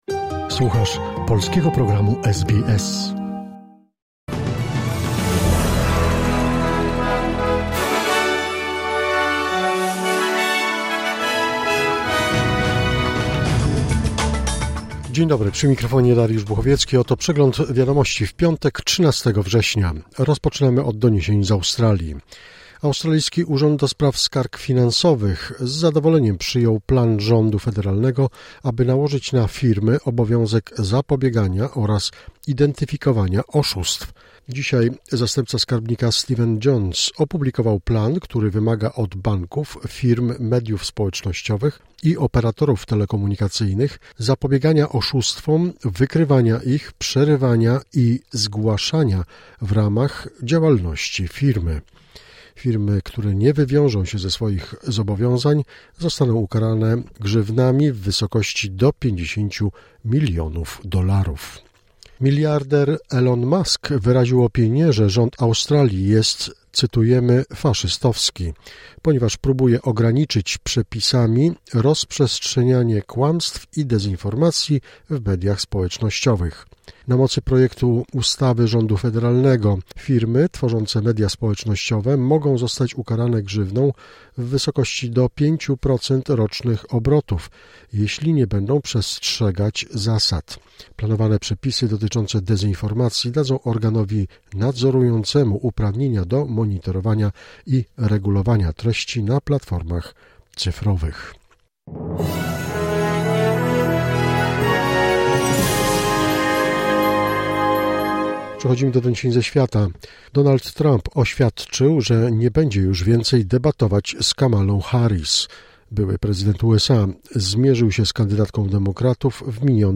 Wiadomości 13 września 2024 SBS News Flash